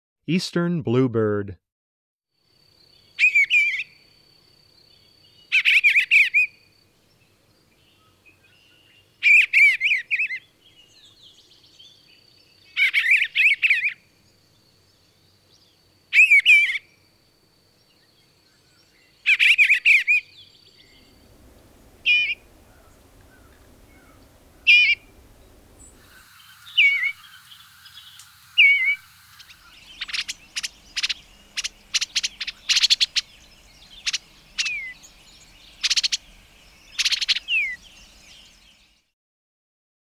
eastern_bluebird.m4a